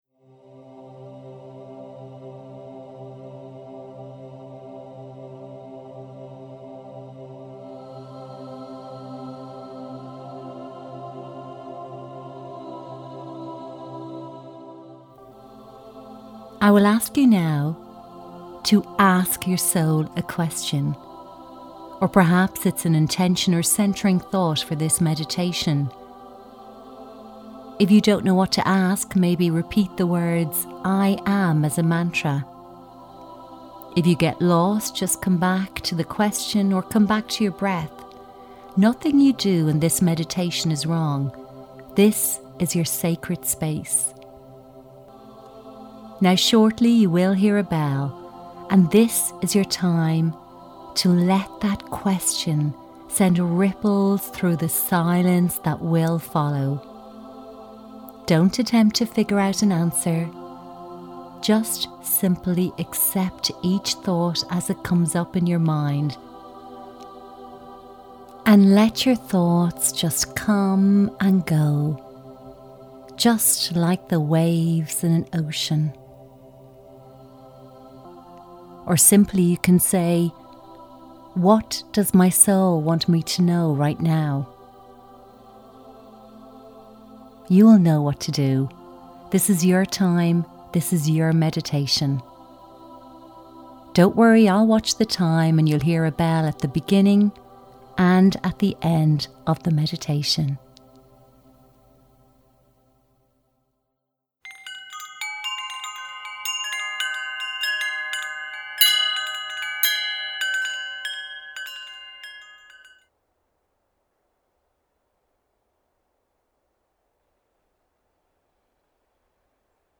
Self Inquiry meditation without full guided introduction. Instead a small intro and 20 minutes of quiet time for soul questions and self inquiry.